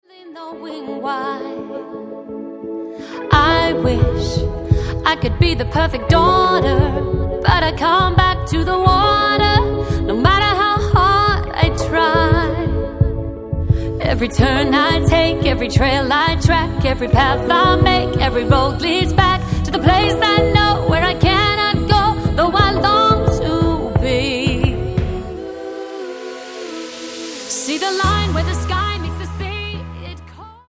Tonart:E-F Multifile (kein Sofortdownload.
Die besten Playbacks Instrumentals und Karaoke Versionen .